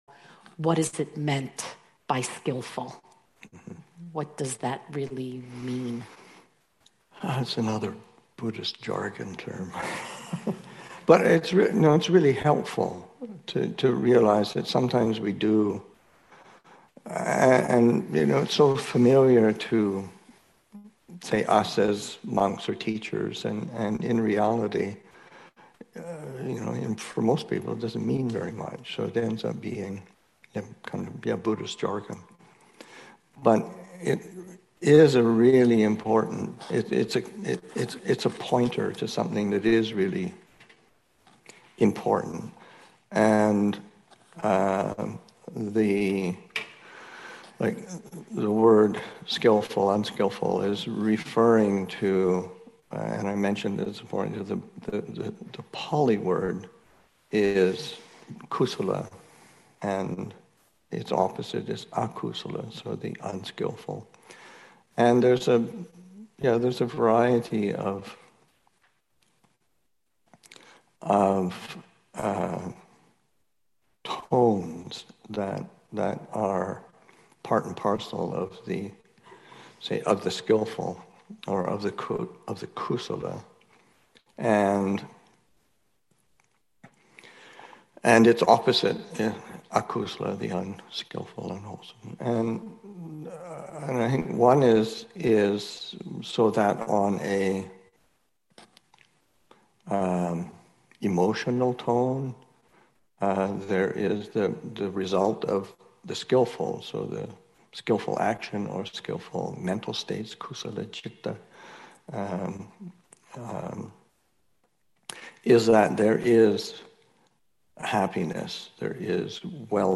Spirit Rock Daylong, Aug. 20, 2023